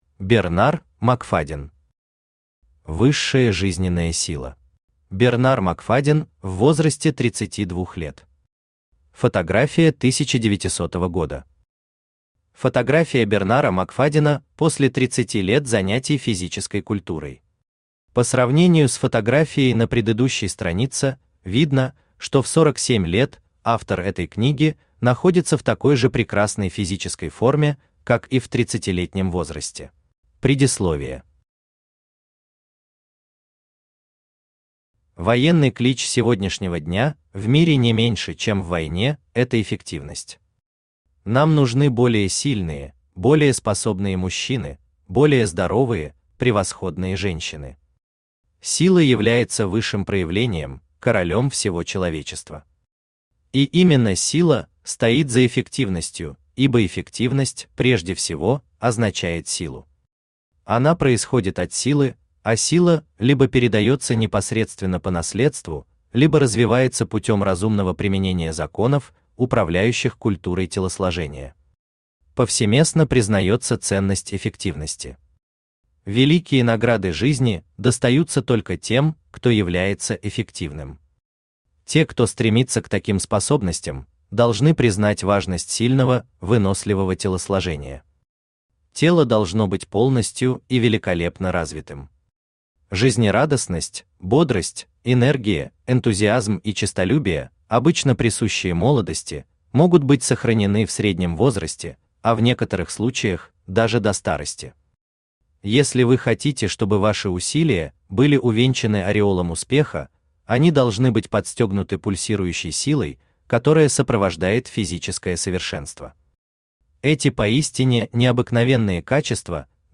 Аудиокнига Высшая жизненная сила | Библиотека аудиокниг
Aудиокнига Высшая жизненная сила Автор Бернар Макфадден Читает аудиокнигу Авточтец ЛитРес.